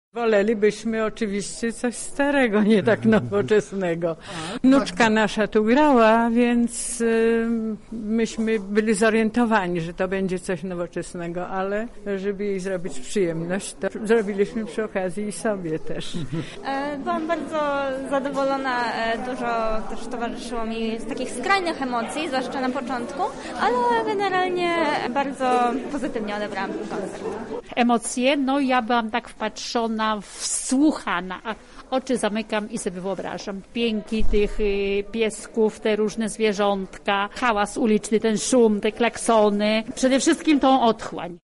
O wrażeniach opowiedzieli uczestnicy: